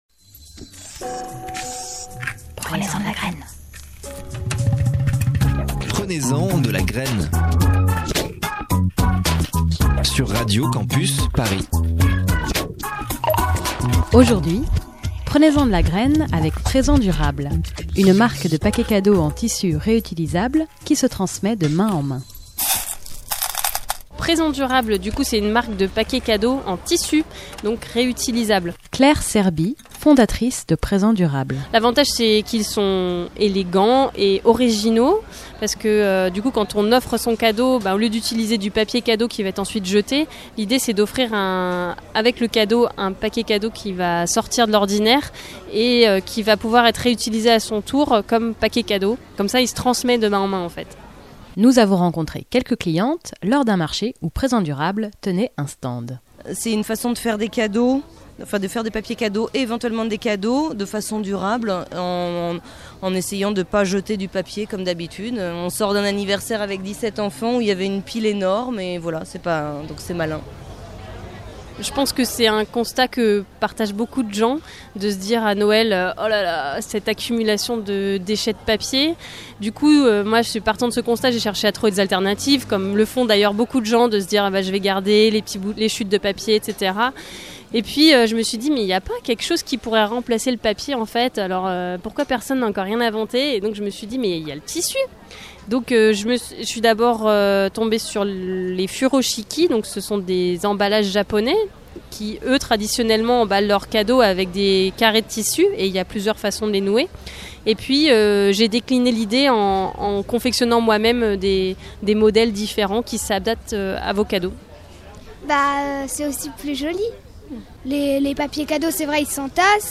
Interview et montage